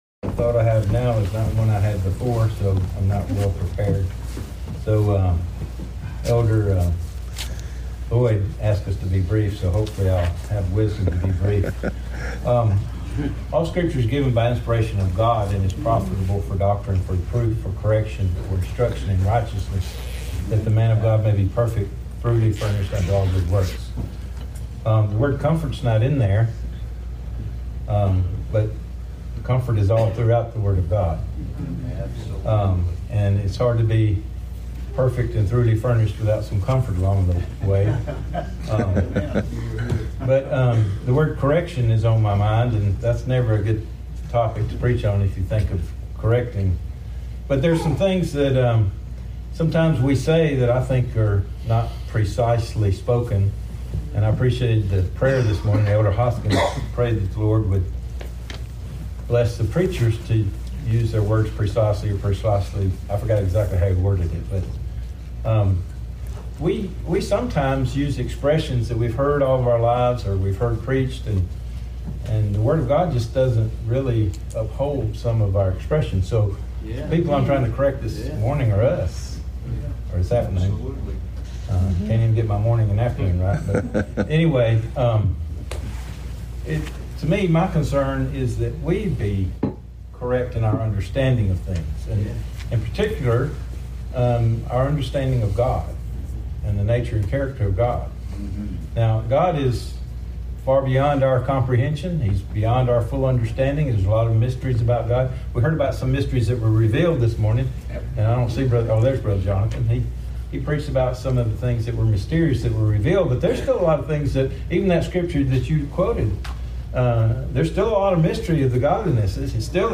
Posted in Little River August 2023 Meeting